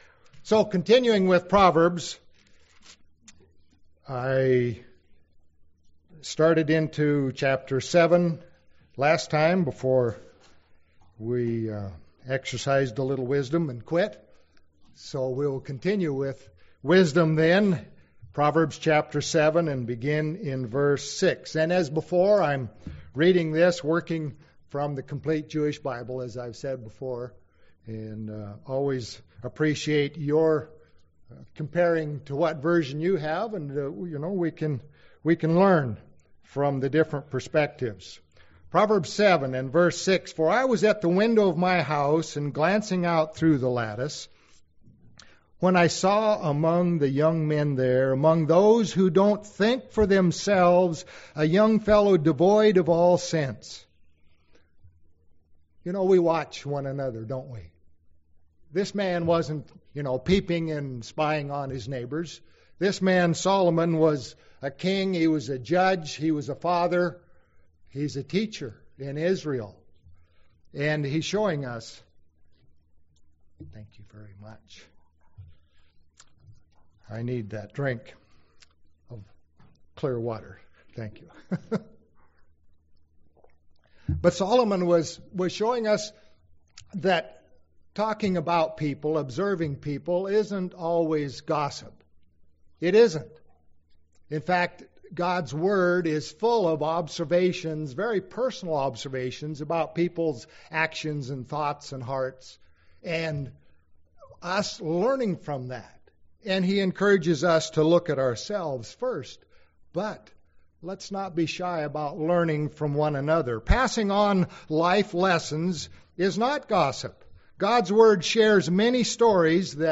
Sermons
Given in Medford, OR